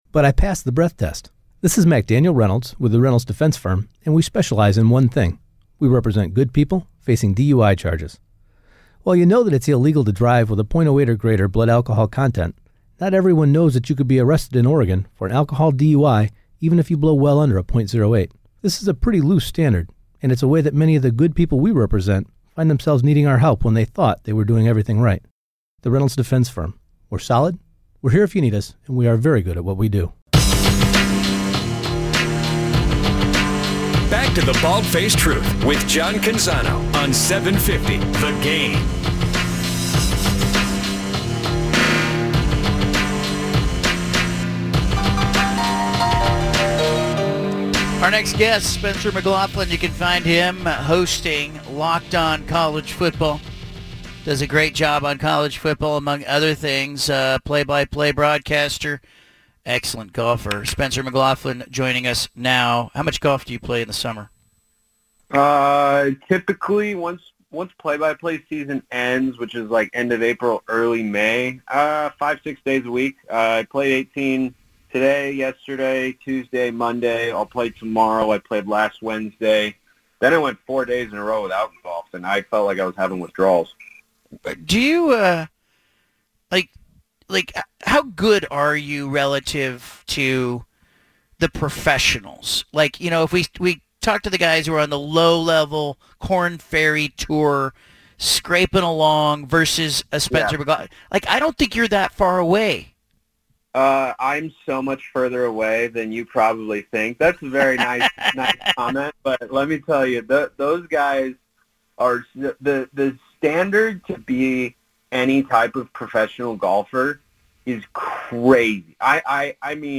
BFT Interview